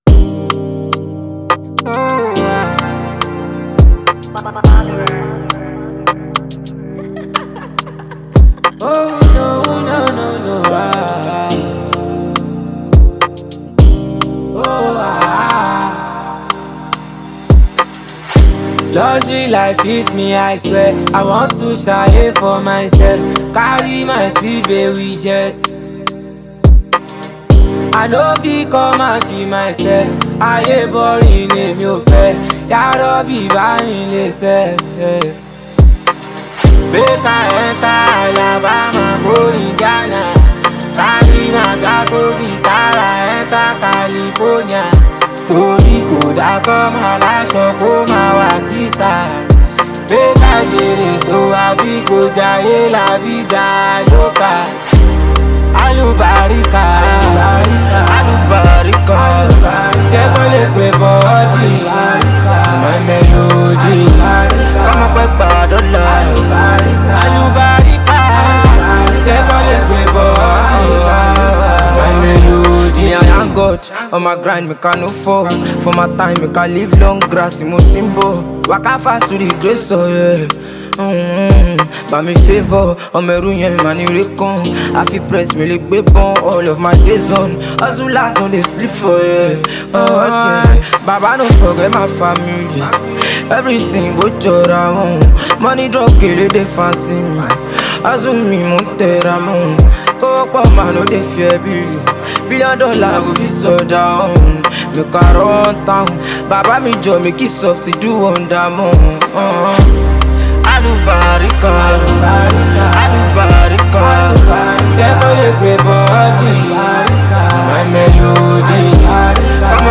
Nigerian singers